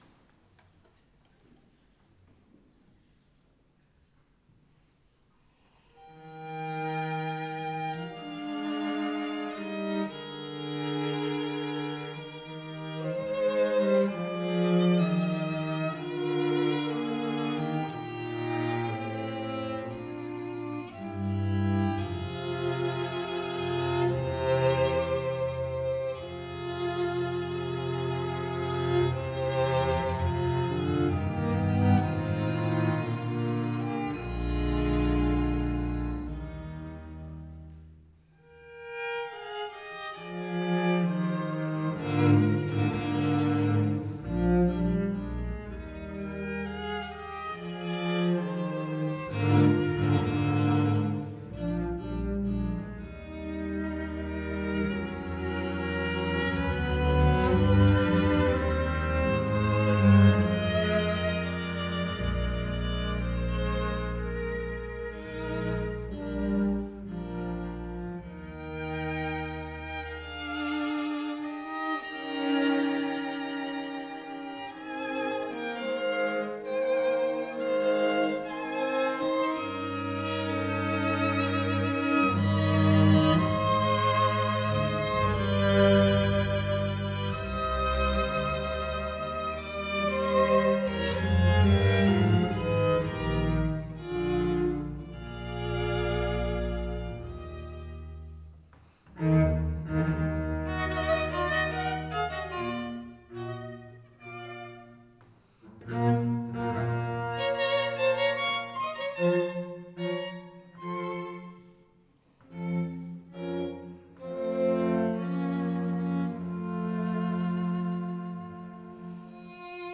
Exklusive Ensemble-Einrichtungen für Streichquintett und -sextett Besetzung